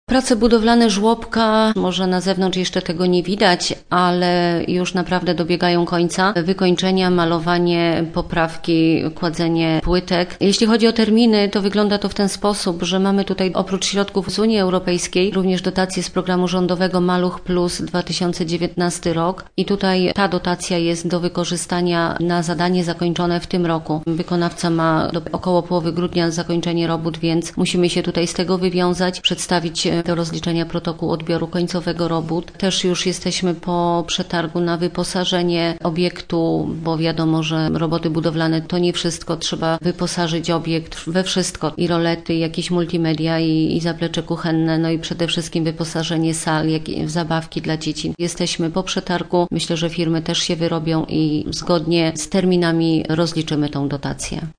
Mówi zastępca wójta gminy Osjaków, Renata Kostrzycka: Prace budowlane żłobka, może na zewnątrz jeszcze tego nie widać, ale już naprawdę dobiegają końca – wykończenia, malowanie, poprawki, kładzenie płytek.